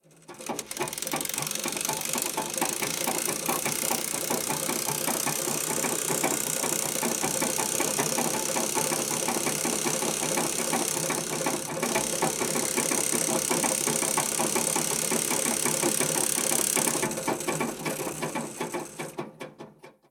Máquina de coser enhebrando
máquina de coser
Sonidos: Industria
Sonidos: Hogar